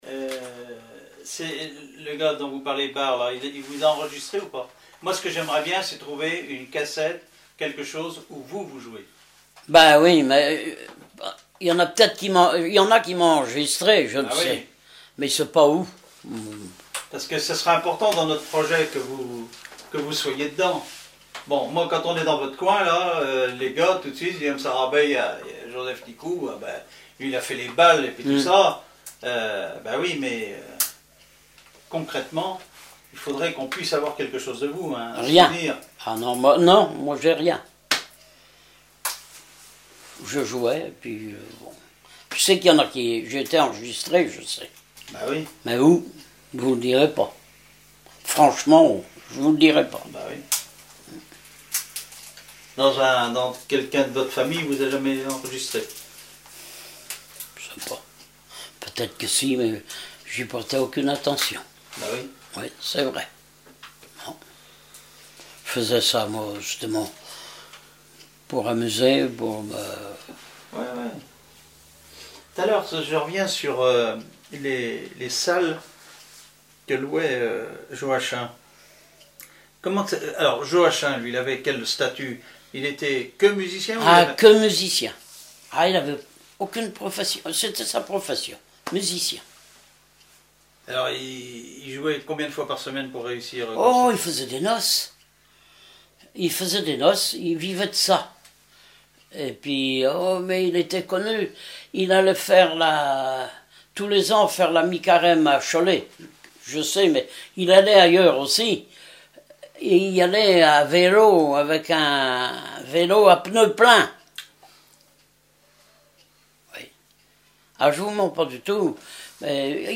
Enquête Compagnons d'EthnoDoc - Arexcpo en Vendée
Catégorie Témoignage